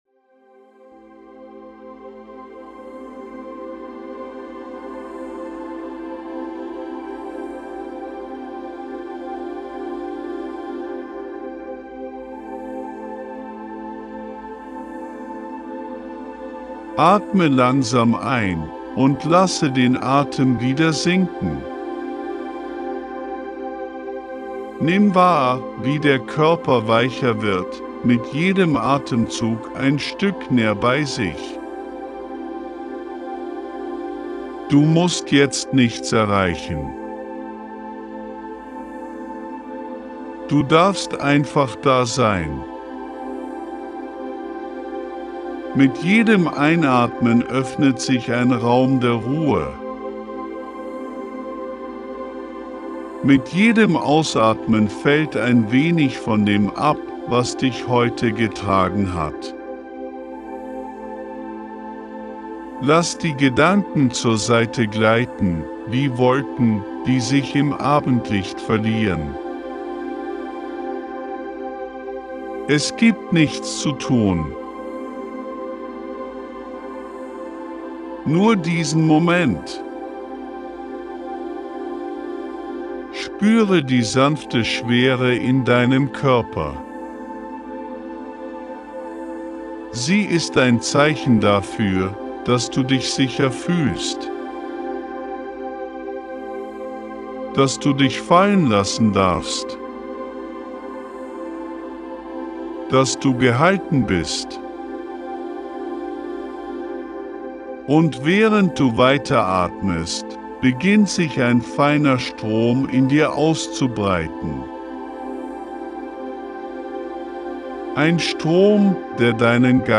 🎧 Die Audio-Meditation zum